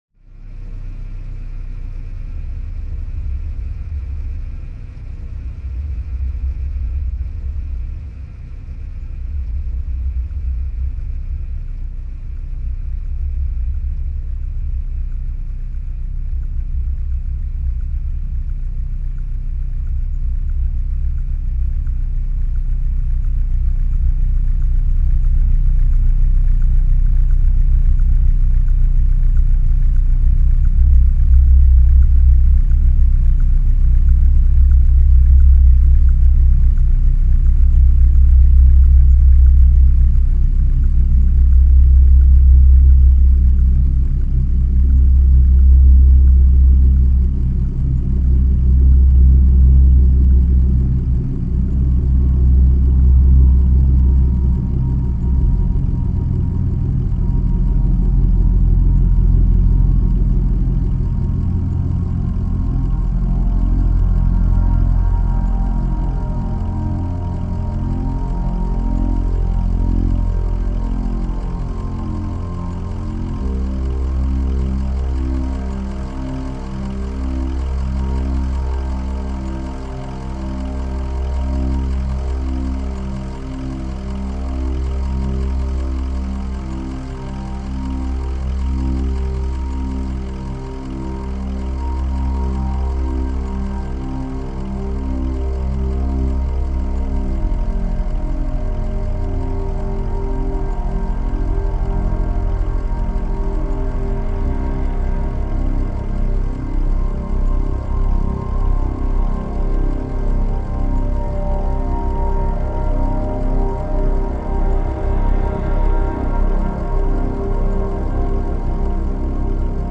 Les éléments musicaux émergent lentement du Sound Design (ici, comme si on suivait un personnage qui avance lentement entre des voitures). Il y a deux intensités différentes (ce qui s’entent surtout dans les instruments basse)
Intensité Basse: